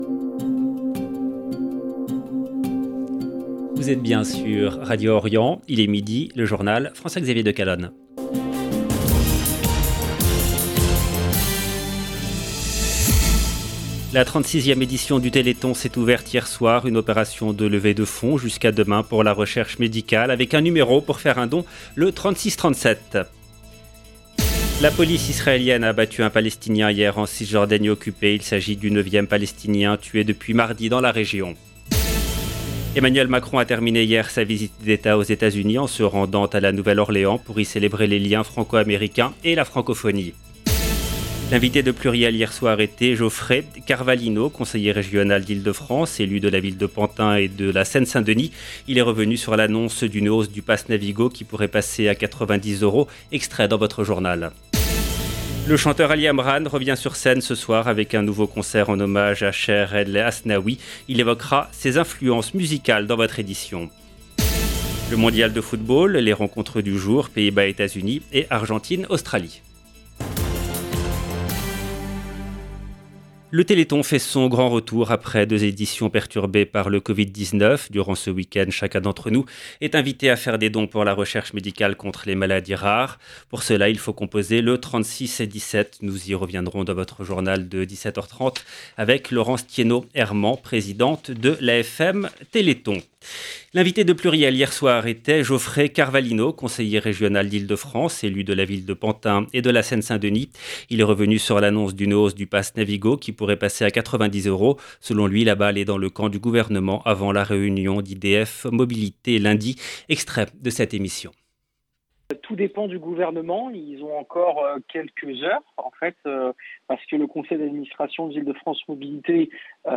EDITION DU JOURNAL DE 12H EN LANGUE FRANCAISE DU 3/12/2022